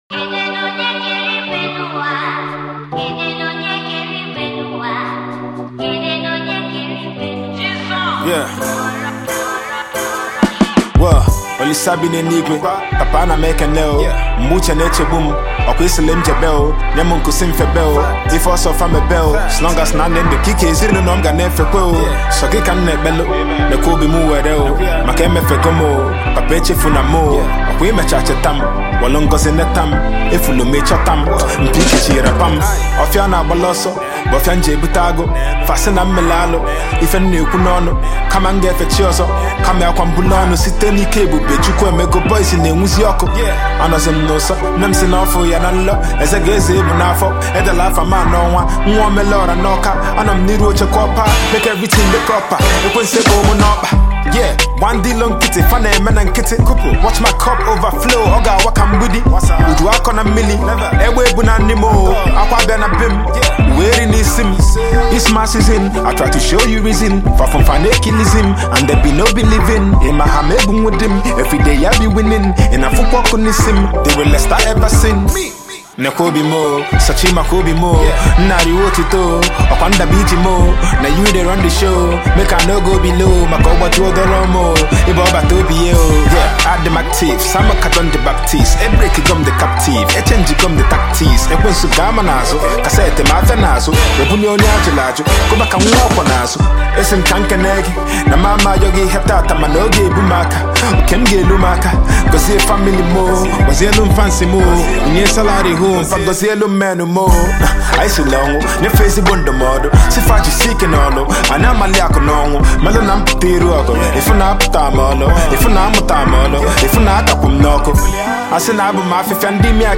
melodious track